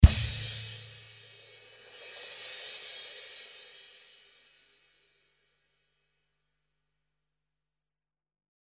This drum beat is in 104 bpm in 6/8 time signature.
A lot of cymbals and 8 different drum fills.